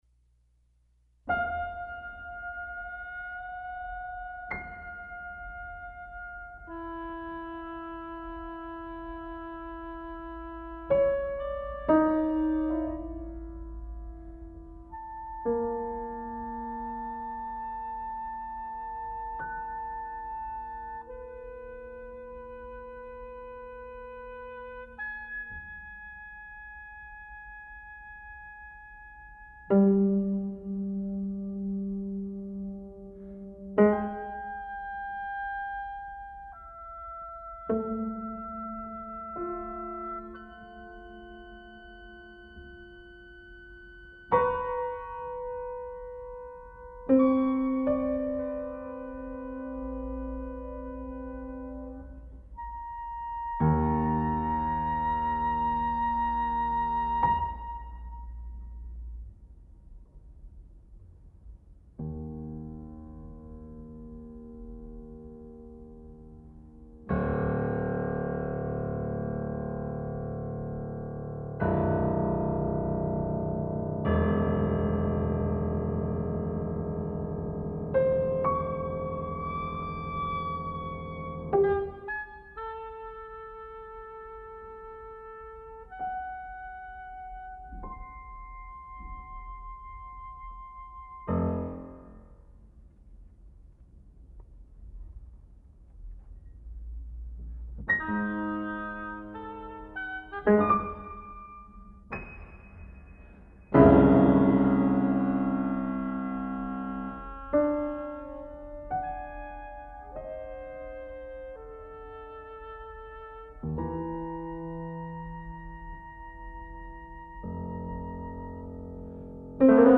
a chamber work